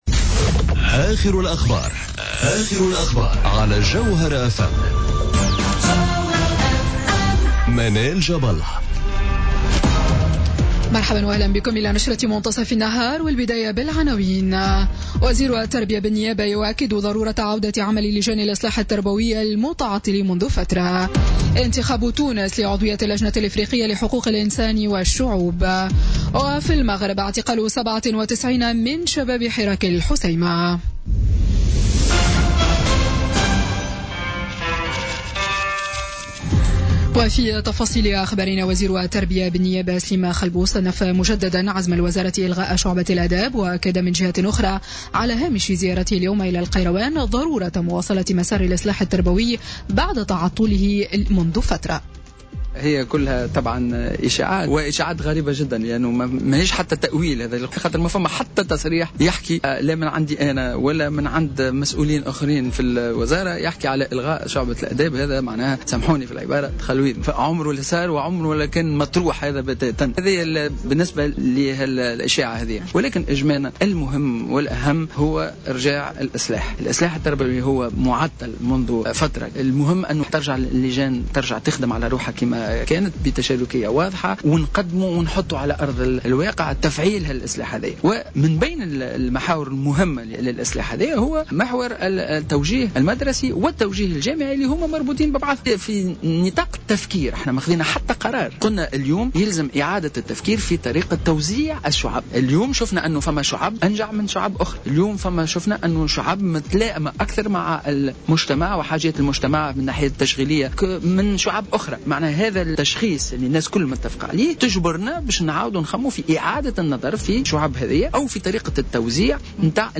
نشرة أخبار منتصف النهار ليوم السبت 1 جويلية 2017